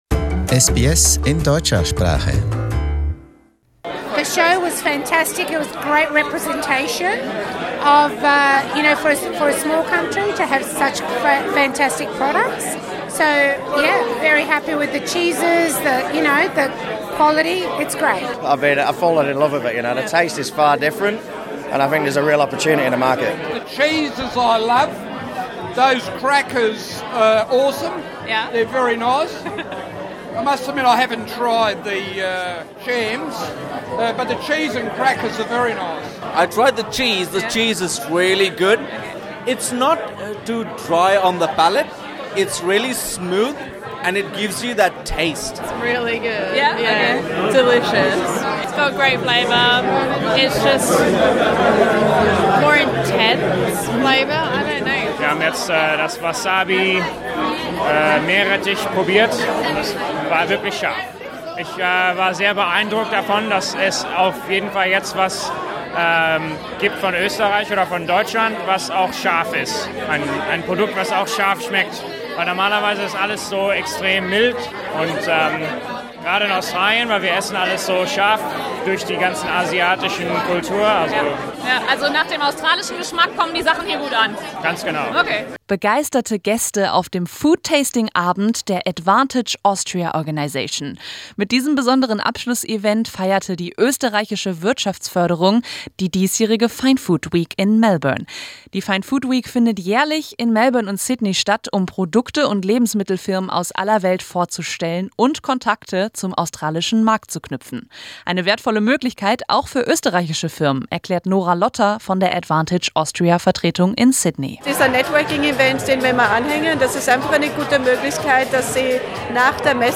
Austrian exhibitors were once again present at this year´s Fine Food in Melbourne.